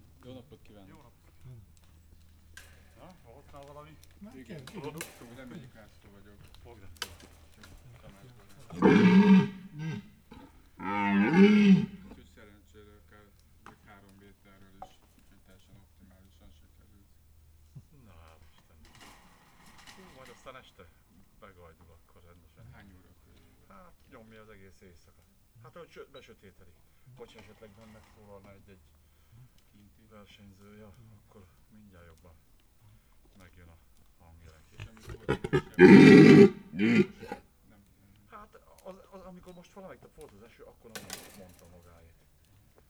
Directory Listing of /_MP3/allathangok/budakeszizoo2010_standardt/gimszarvas/
gimszarvas_beszelgeteskozbenboges00.42.WAV